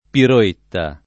piroetta [ piro % tta ]